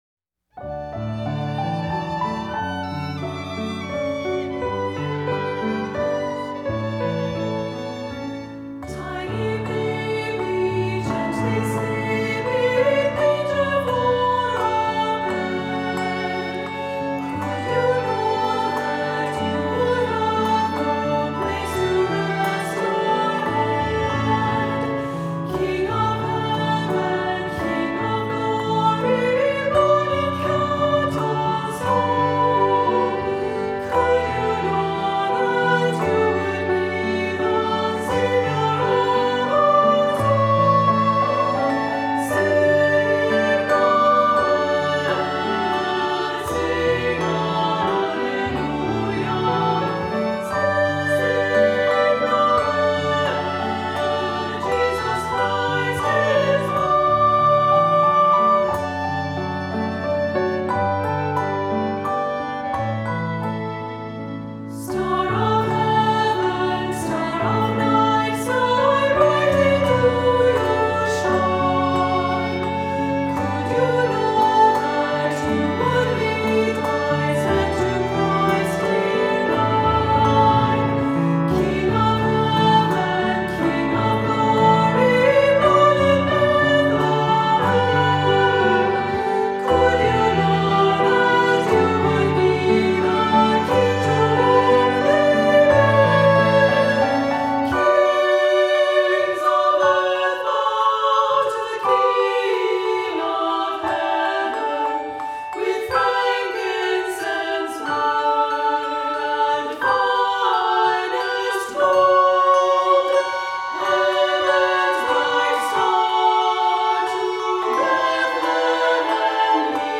Voicing: Two-part Children's Choir; Cantor; Descant